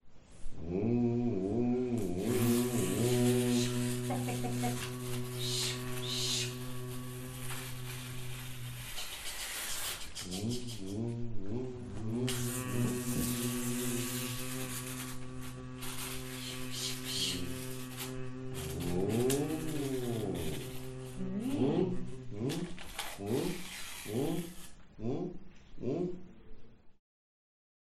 L’atelier se déroule dans une salle de séminaire avec une grande table centrale, des chaises pour s’assoir autour, mais peu d’espace pour circuler ou faire de grands mouvements du corps.
Performance Groupe 3. Durée : 1’04”, un extrait de 28” :